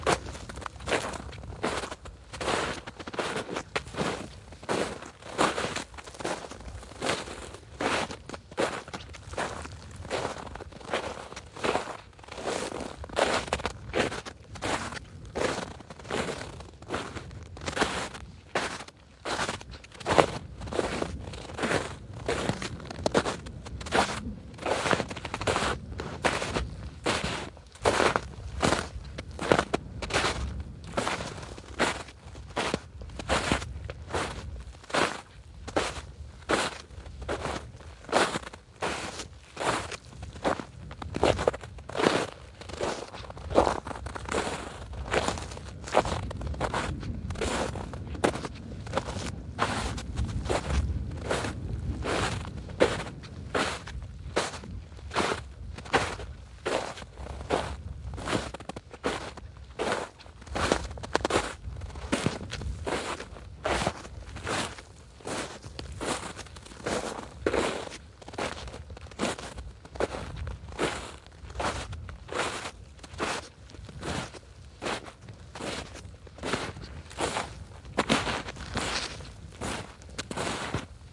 雪崩03
描述：雪在下脚踩着
标签： 脚步 湿 一步 在户外 紧缩
声道立体声